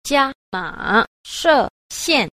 10. 伽瑪射線 – jiāmǎshèxiàn – gia mã xạ tuyến (tia gamma)